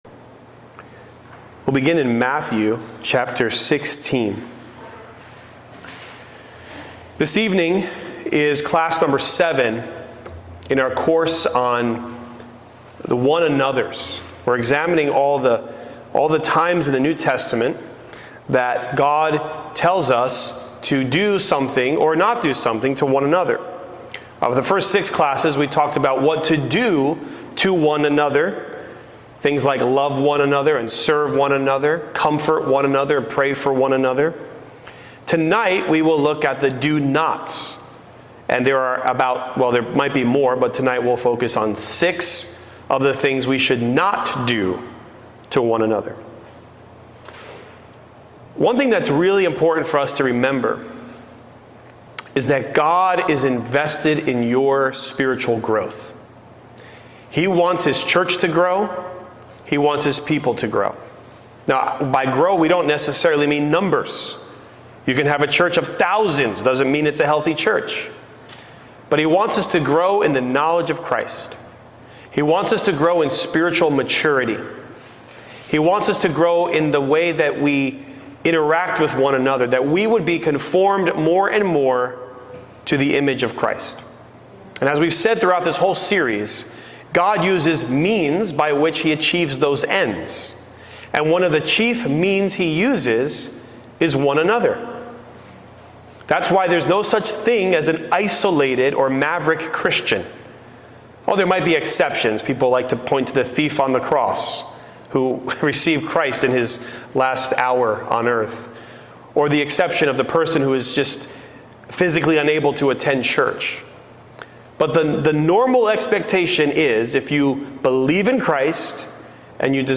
The "Do Nots" to One Another | SermonAudio Broadcaster is Live View the Live Stream Share this sermon Disabled by adblocker Copy URL Copied!